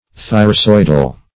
thyrsoidal.mp3